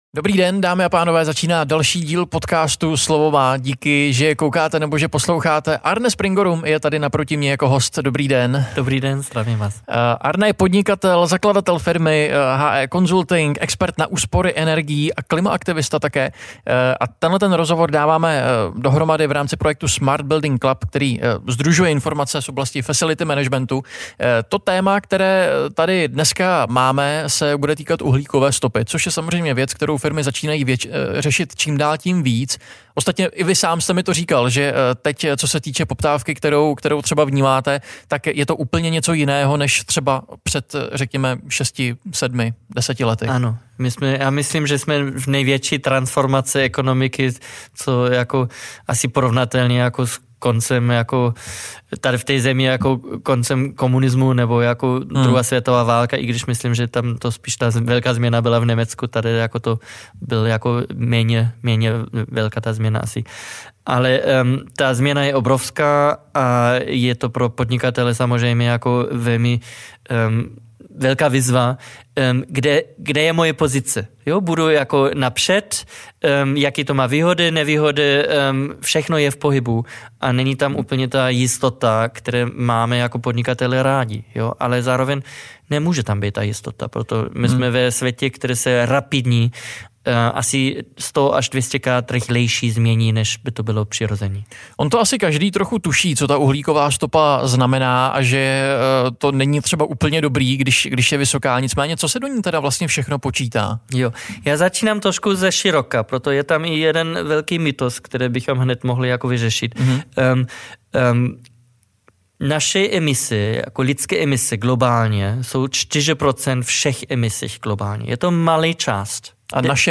Jakému druhu firmy se nejvíce vyplatí přejít na nějaký udržitelný zdroj energie a uhlíkovou stopu snížit? Rozhovor vznikl v rámci projektu Smart Building Club, který sdružuje informace z oblasti facility managementu.